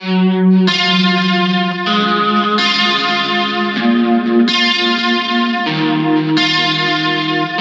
描述：流行乐|激励
标签： 钢琴 acoustic gutiar
声道立体声